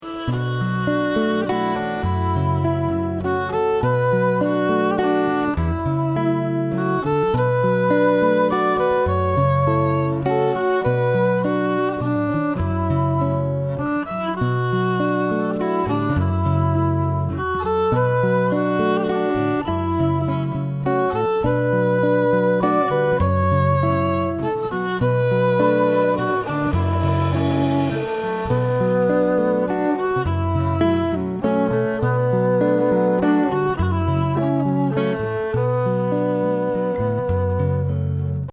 Exciting and innovative five-piece band